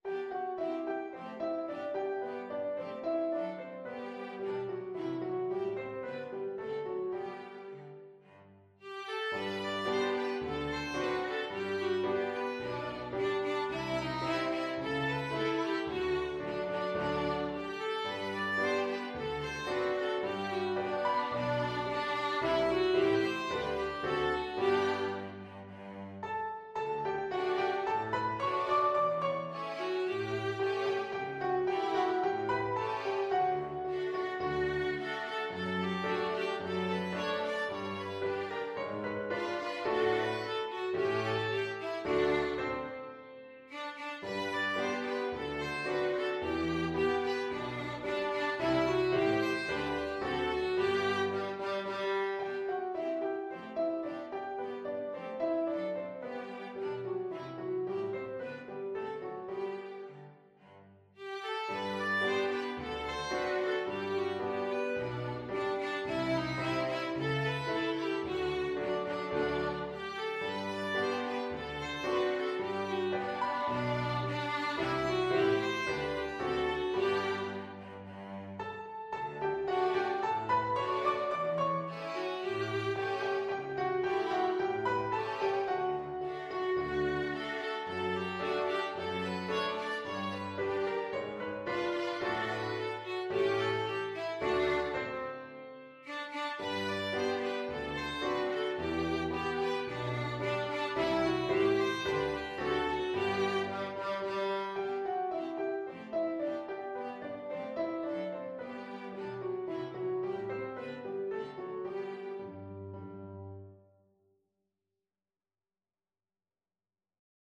Piano Quintet version
Violin 1Violin 2ViolaCelloPiano
4/4 (View more 4/4 Music)
Allegro moderato =110 (View more music marked Allegro)
Classical (View more Classical Piano Quintet Music)